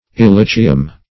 Illicium - definition of Illicium - synonyms, pronunciation, spelling from Free Dictionary
Illicium \Il*li"ci*um\, n. [So called, in allusion to its aroma,